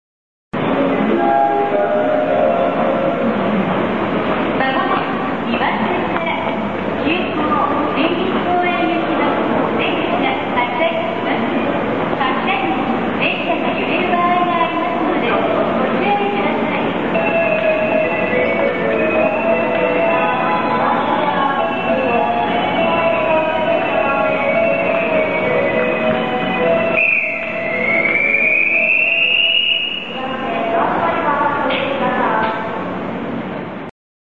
発車放送ﾒﾛﾃﾞｨ・女性（急行・森林公園） Passenger DVC ※音量注意